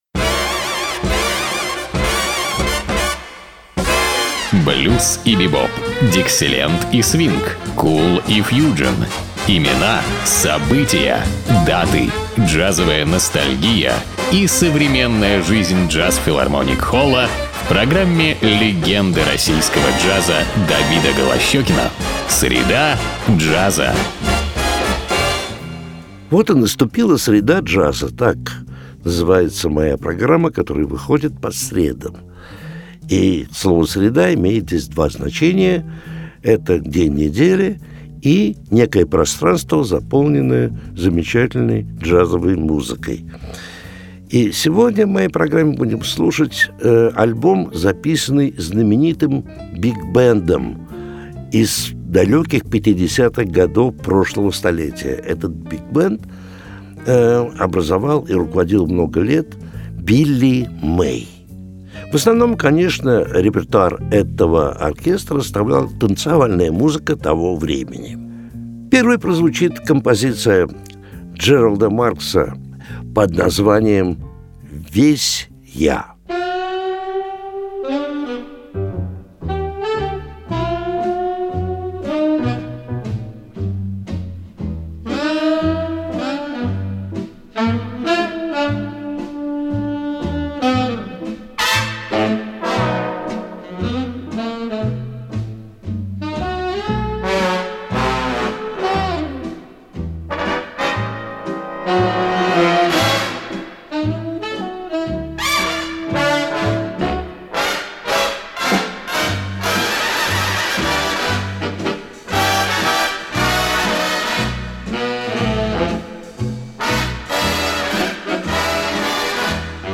Великолепный свинг середины ХХ века